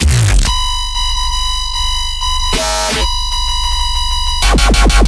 描述：Mouse trap snapping.
标签： cheese mouse ouch snap trap
声道立体声